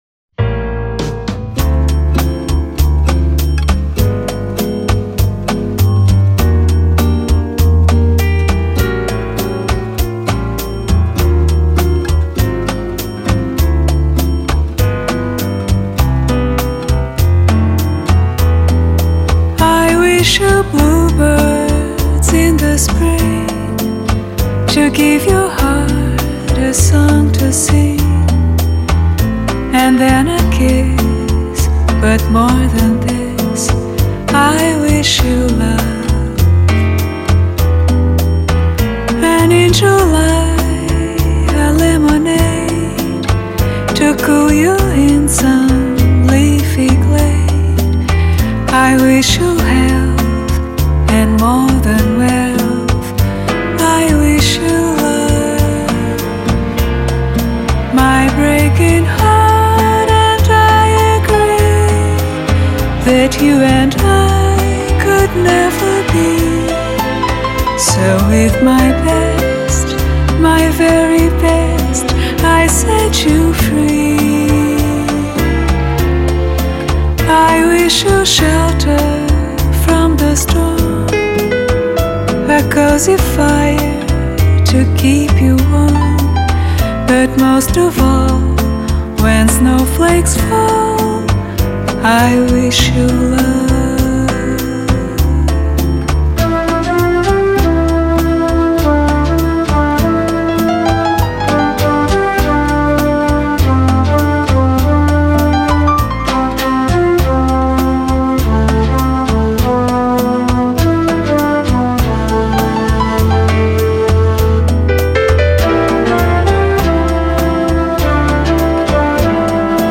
音樂類型 : 爵士樂  Bossa Nova[center]